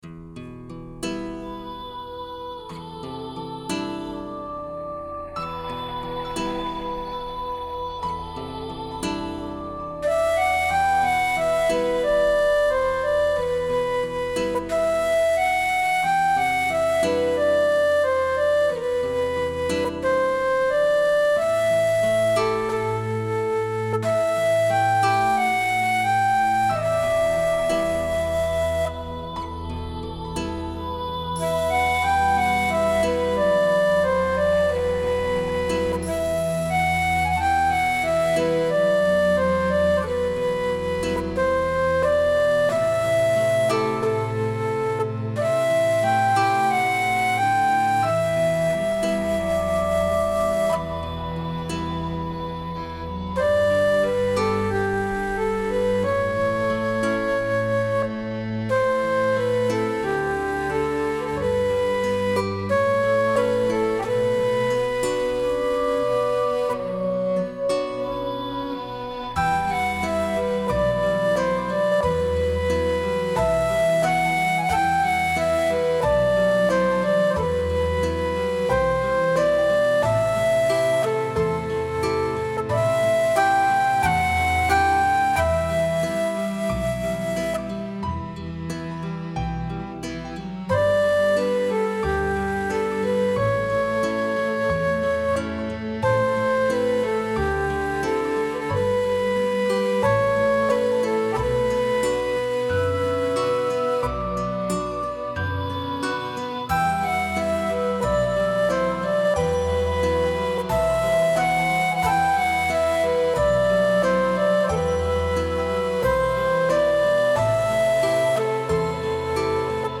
Complete arrangement